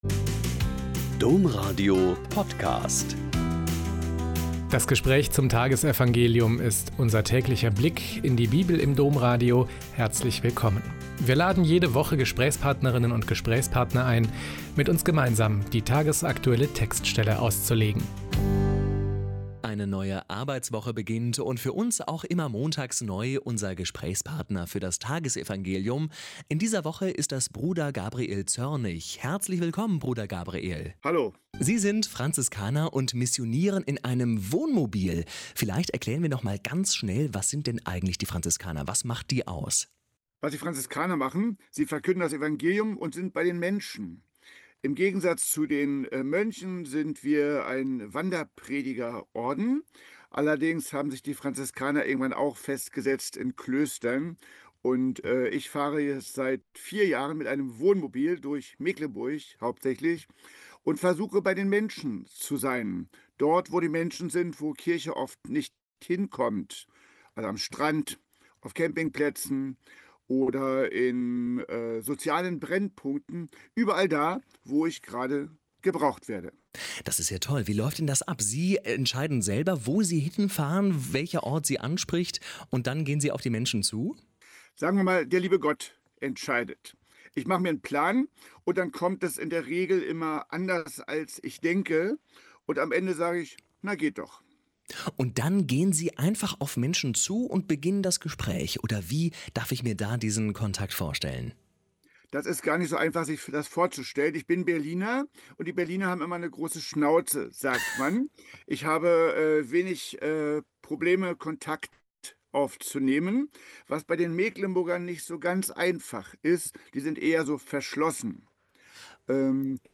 Mt 14,13-21 - Gespräch